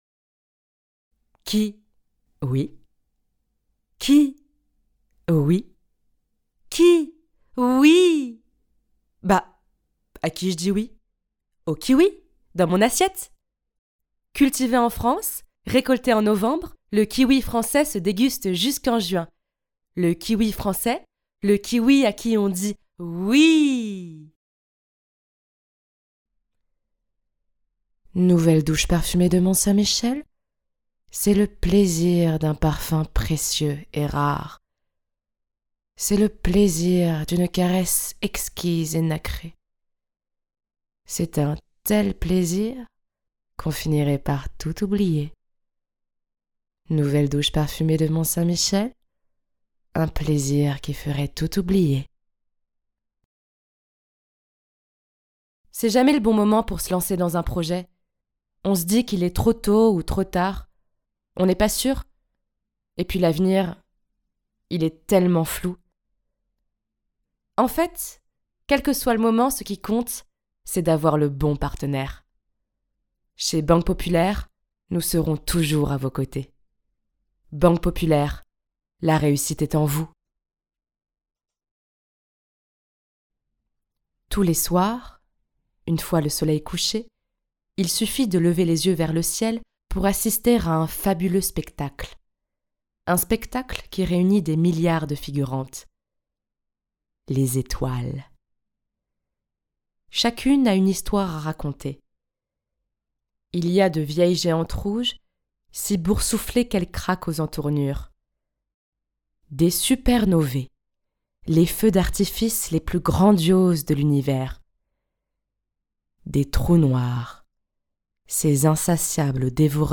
Bande démo voix-off